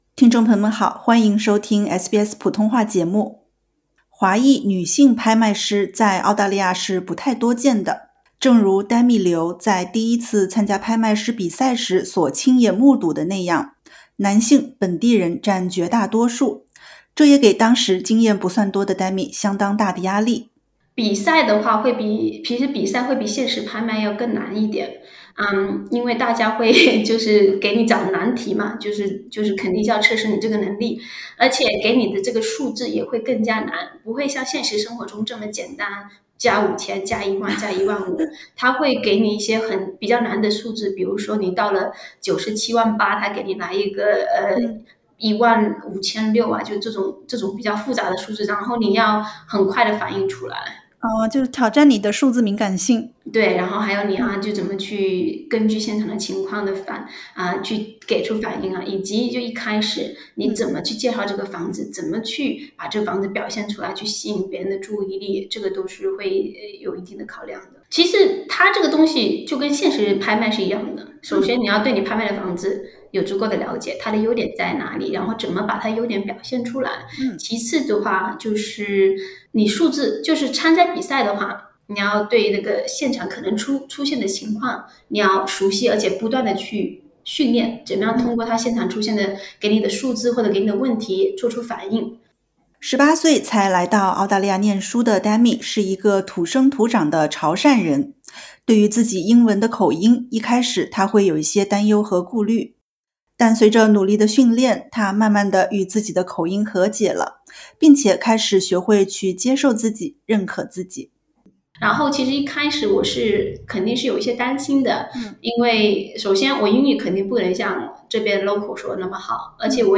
（点击图片收听完整对话） 热情、自信地向在场所有竞拍买家介绍房源，随后游刃有余地拿捏着现场的气氛与节奏，最终以超出预期的价格拍出房源